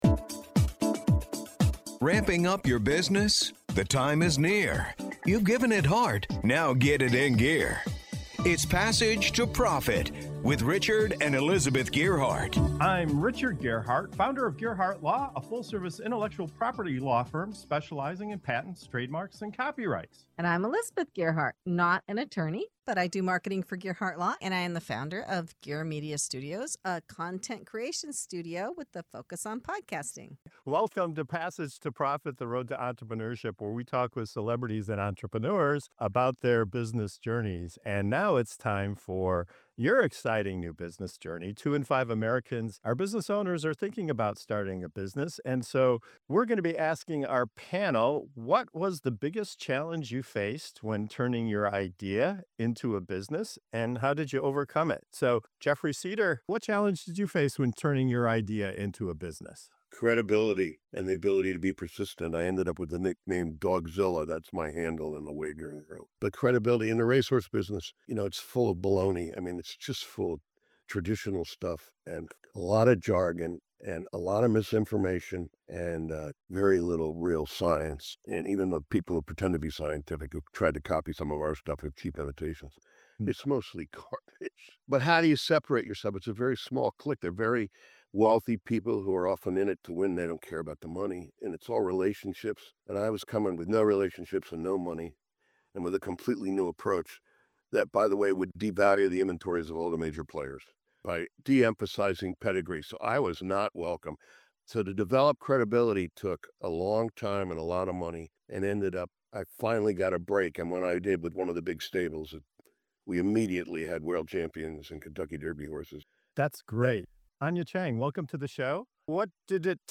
If you’ve ever wondered how successful entrepreneurs push through doubt, rejection, and setbacks — this conversation will inspire you to keep going.